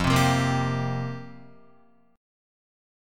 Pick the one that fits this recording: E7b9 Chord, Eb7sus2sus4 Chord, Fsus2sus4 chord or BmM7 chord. Fsus2sus4 chord